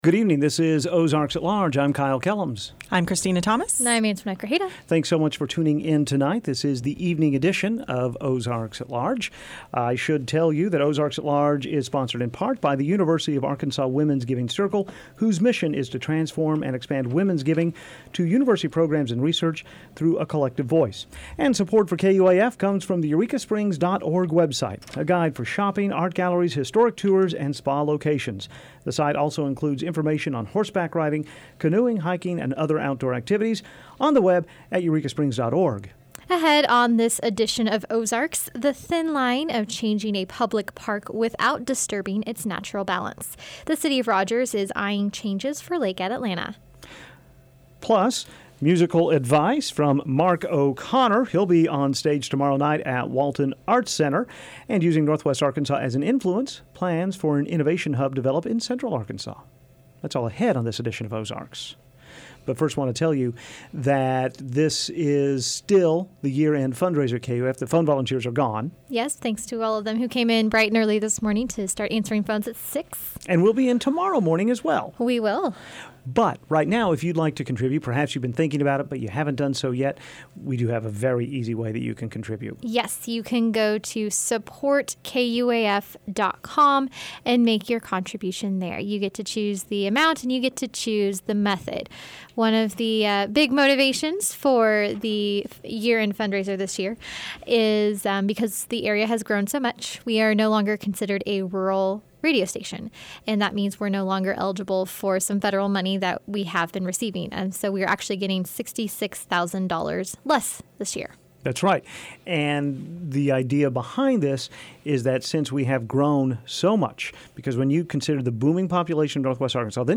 Transition Music: March of the Trolls by Edvard Greig